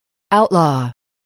단어번호.0661 대단원 : 3 소단원 : a Chapter : 03a 직업과 사회(Work and Society)-Professions(직업) outlaw [áutlɔ́ː] 명) 무법자, 범법자 동) 불법화하다, 금하다 mp3 파일 다운로드 (플레이어바 오른쪽 아이콘( ) 클릭하세요.)